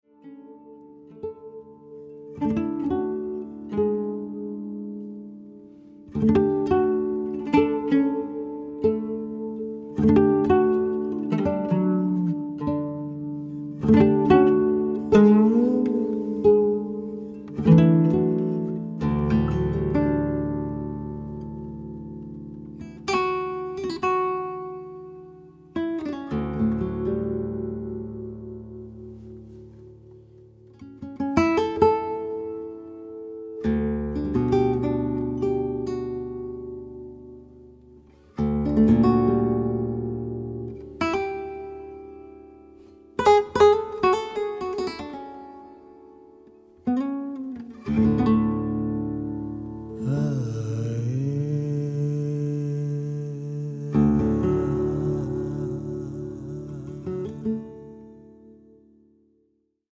voice, oud
su un tappeto di sonorità elettronicamente raffinate.